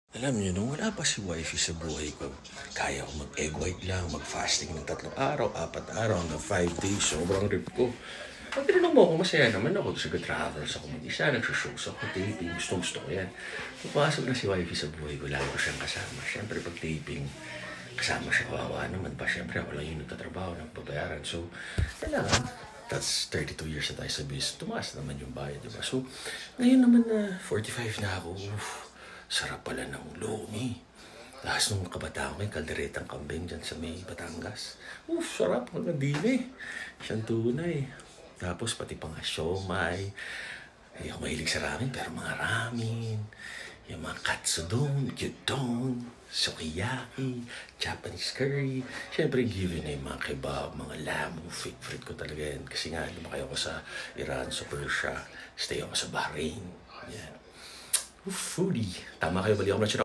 sound effects free download